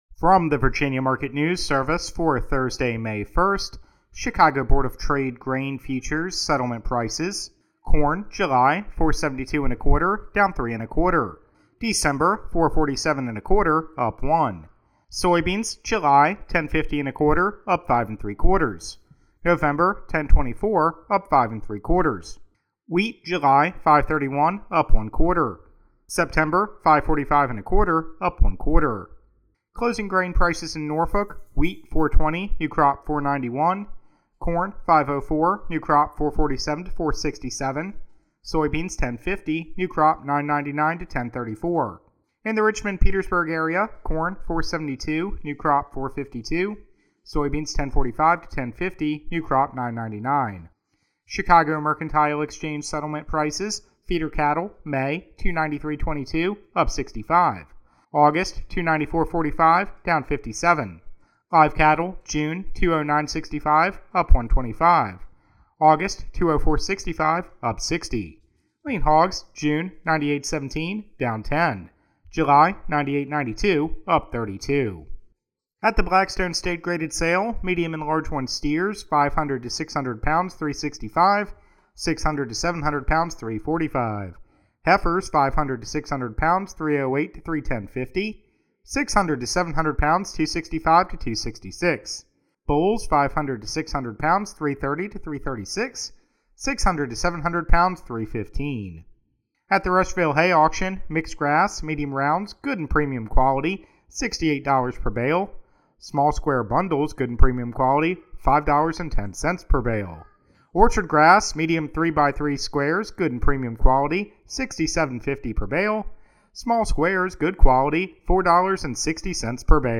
Virginia Market News Service provides daily radio broadcasts that are updated by noon and 5 p.m. In addition, a weekly summary radio report is available on Friday afternoon.